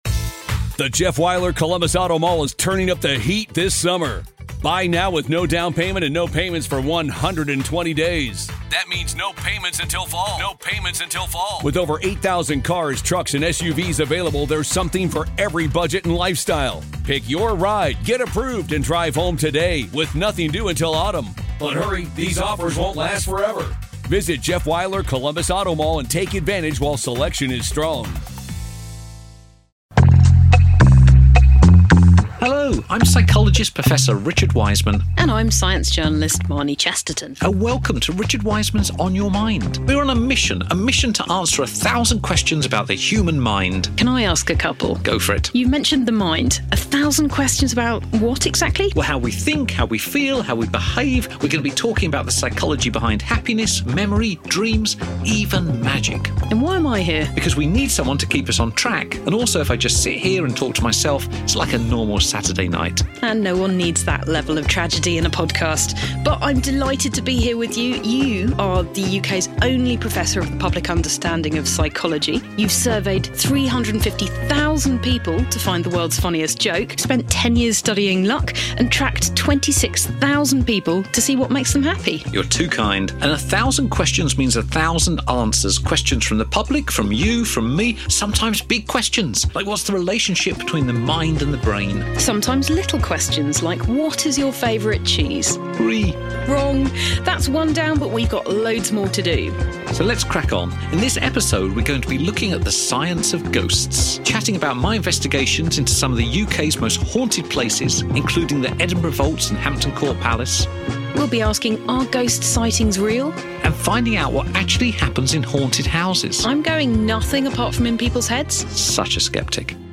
Presented by Professor Richard Wiseman